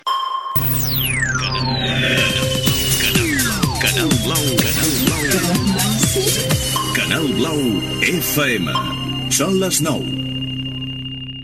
Indicatiu i hora en punt.